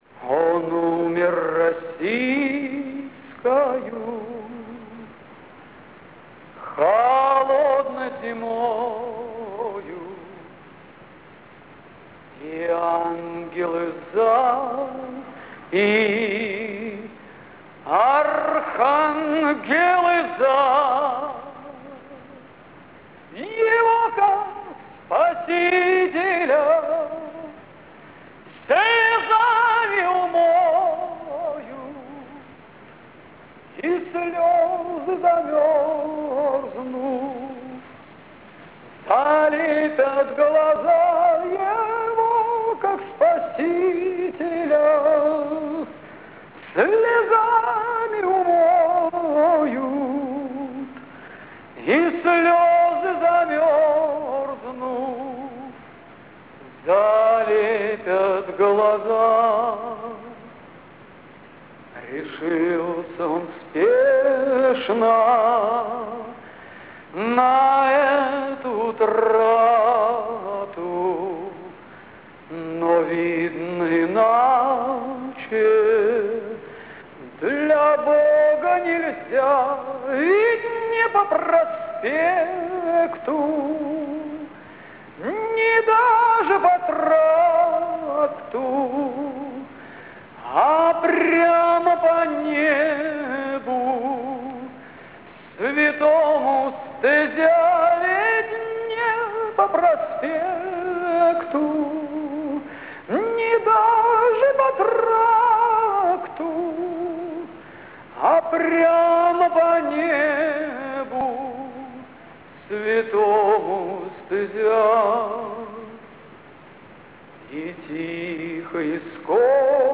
Russian bard